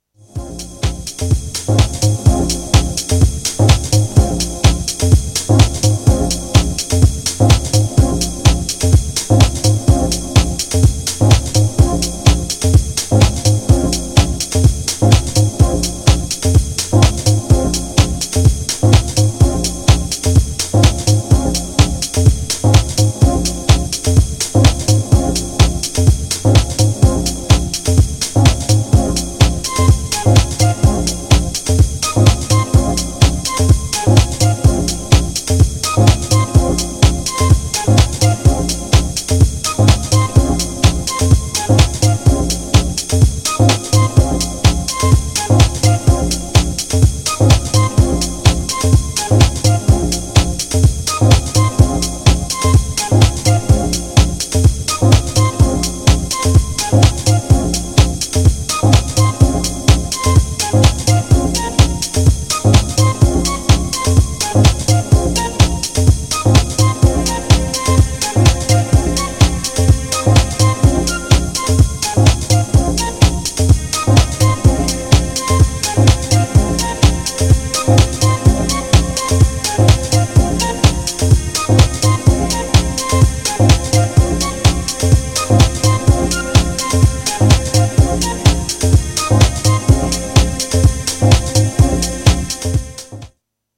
GENRE House
BPM 126〜130BPM